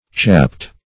(ch[a^]pt or ch[o^]pt); p. pr.